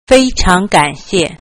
Fēicháng gǎnxiè.